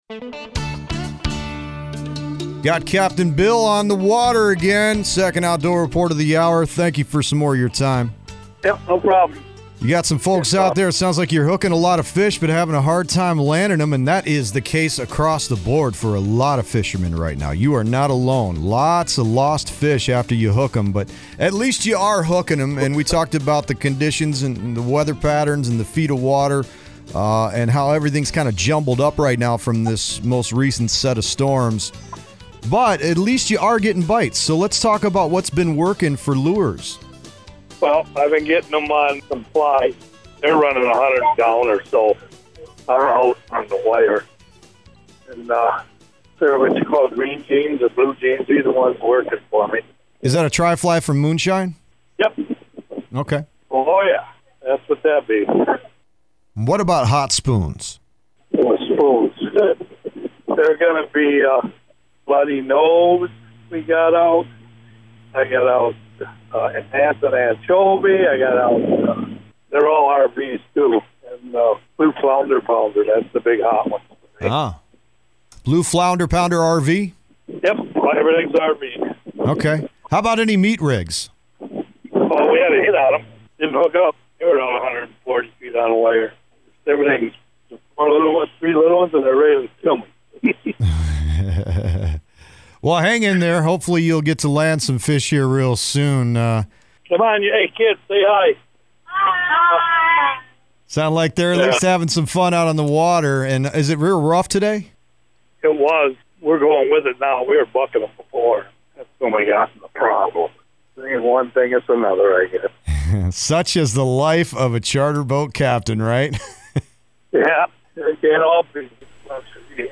joined the show while on the waters of northern Lake Michigan to talk about salmon fishing in the U.P.